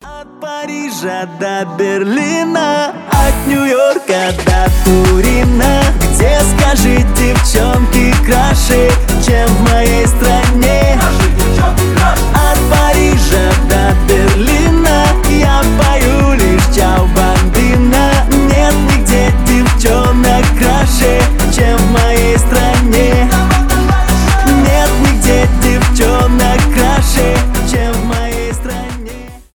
веселые , позитивные , поп